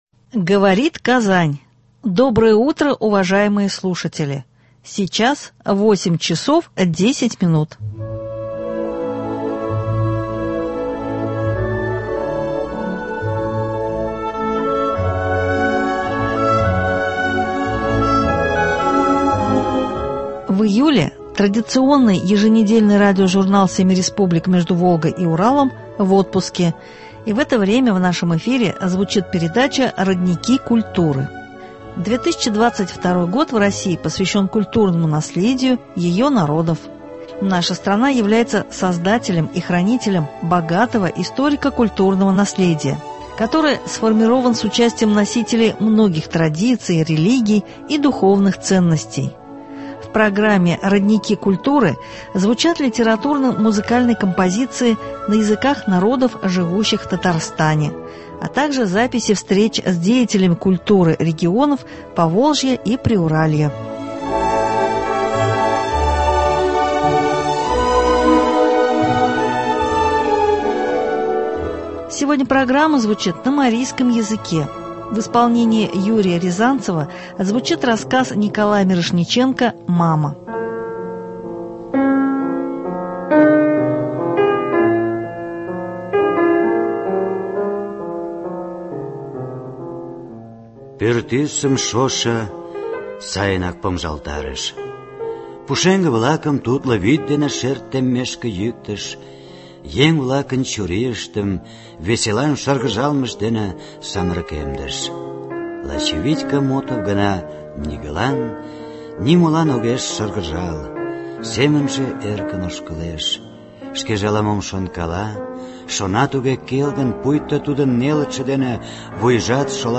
В программе «Родники культуры» звучат литературно — музыкальные композиции на языках народов, живущих в Татарстане, записи встреч с деятелями культуры регионов Поволжья и Приуралья.